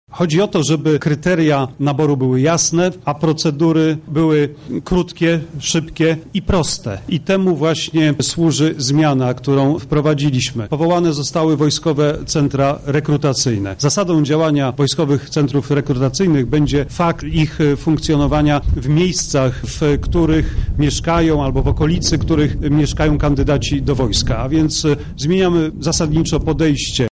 Mówił o tym na konferencji prasowej Mariusz Błaszczak